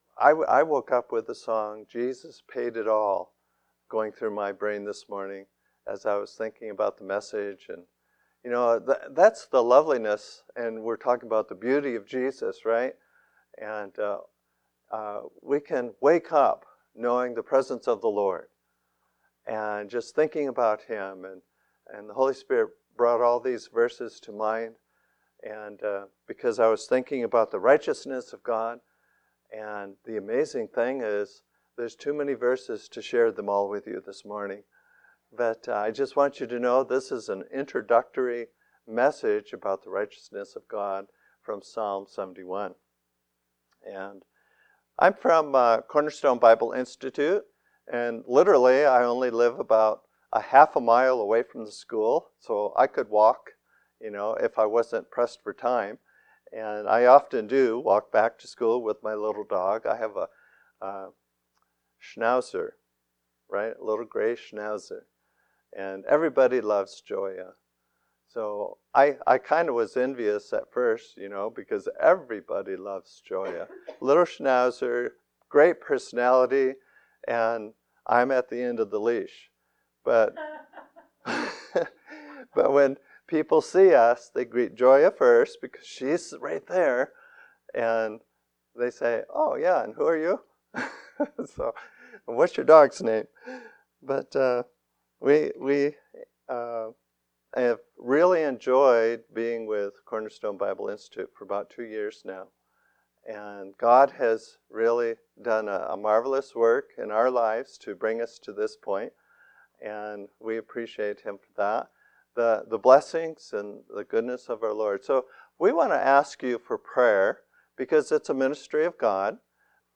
Passage: Psalm 71 Service Type: Sunday Morning Worship